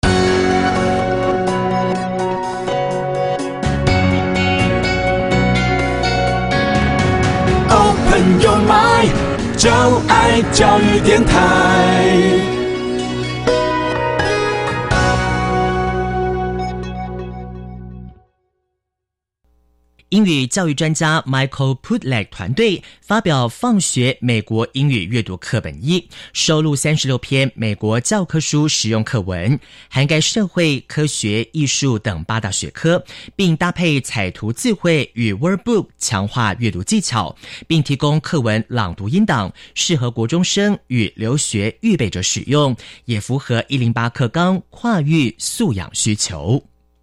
課文朗讀 MP3
完整朗讀課文，一邊讀一邊背，既可增進閱讀理解，也可有效訓練英語聽力。
掃描書封QR Code下載「寂天雲」App，即能下載全書音檔，無論何時何地都能輕鬆聽取專業母語老師的正確道地示範發音，訓練您的聽力。